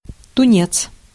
Ääntäminen
France: IPA: [tɔ̃]